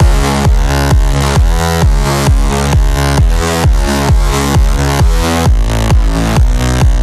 nice and scratchy